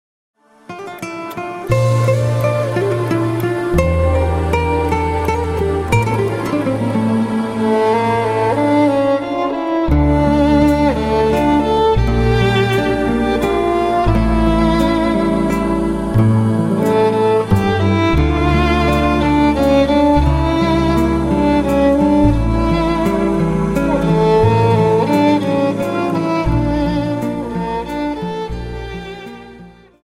Slow Waltz 29 Song